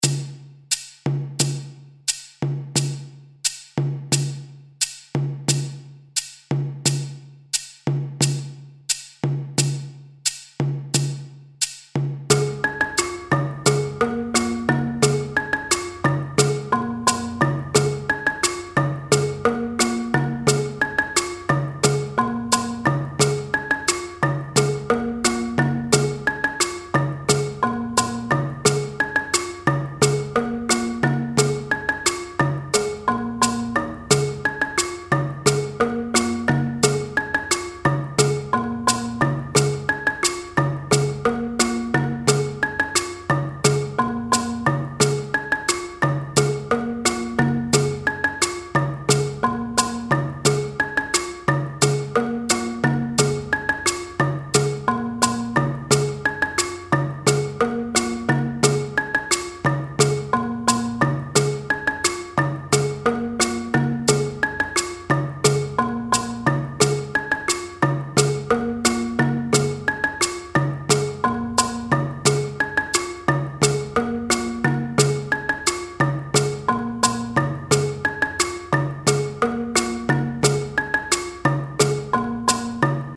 Pentatonische balafoon
176 bpm Patroon A
RitmeMuso-Bwe-176bpm-Patroon-A.mp3